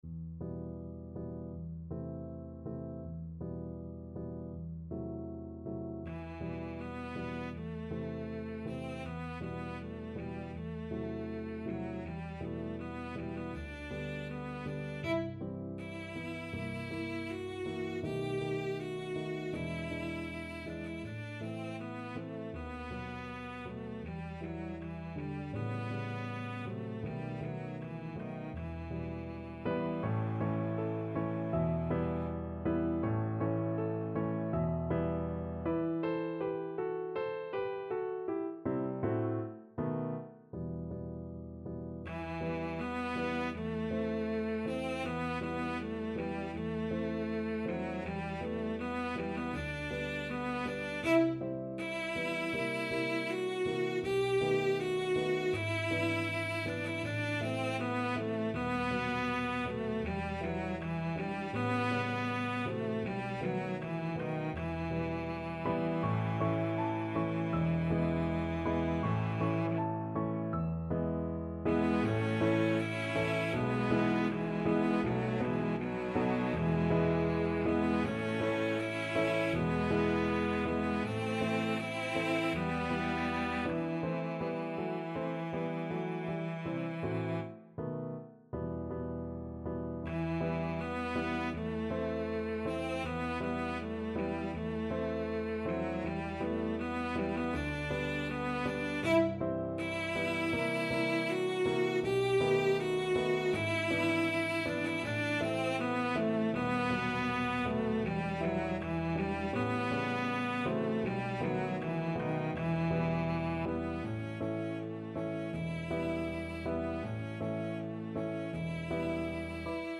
Cello version
~ = 100 Andante
2/4 (View more 2/4 Music)
Cello  (View more Intermediate Cello Music)
Classical (View more Classical Cello Music)